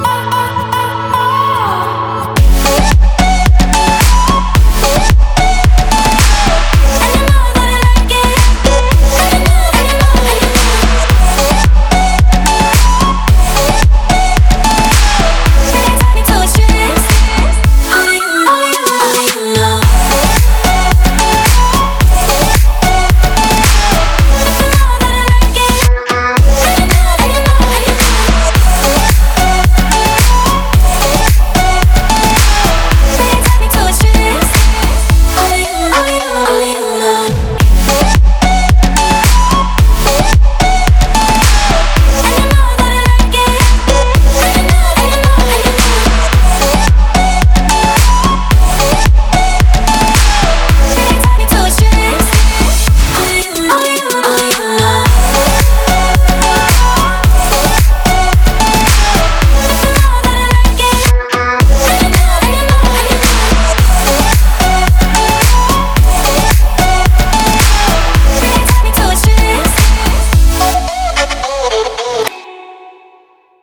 • Качество: 320, Stereo
club
house
Приятный мотив